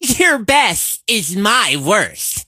carl_kill_vo_02.ogg